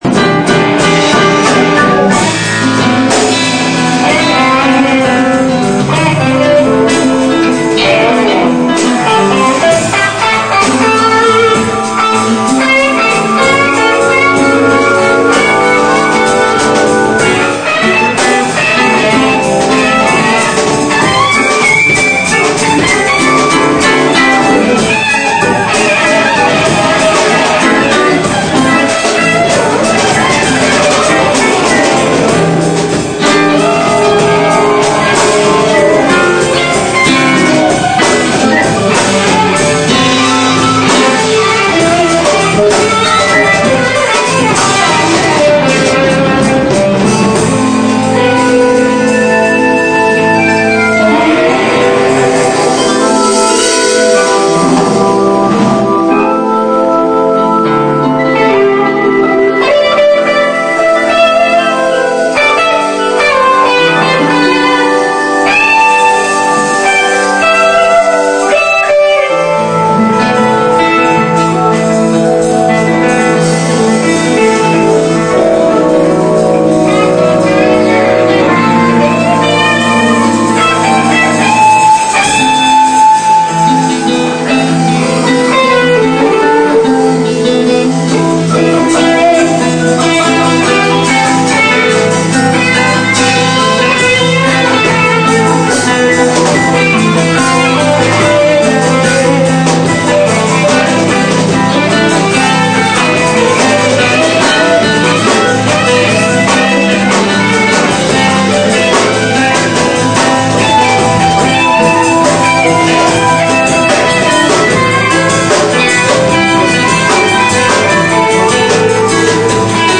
(TECLADOS)
(12 DE DICIEMBRE 2000, IMPROVISANDO CON LOS FLOWER KINGS)
guitarra acústica
gutarra eléctrica
bajo
batería